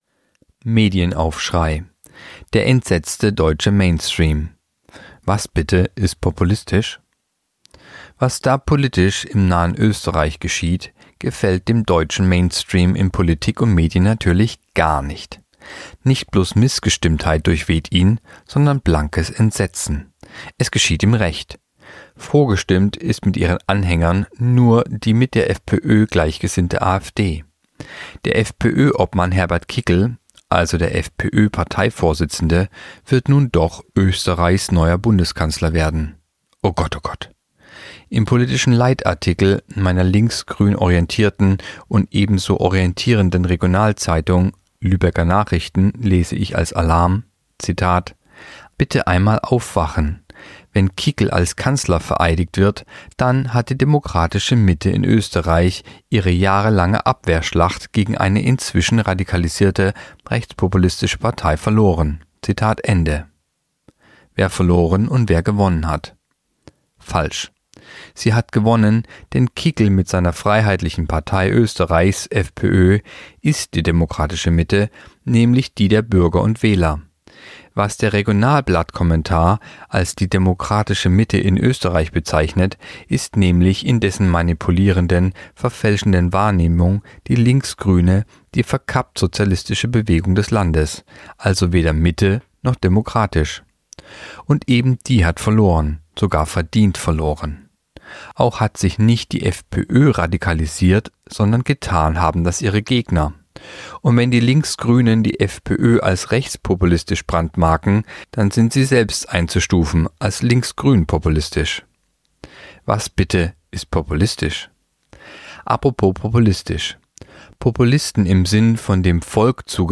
Artikel der Woche (Radio)Der entsetzte deutsche Mainstream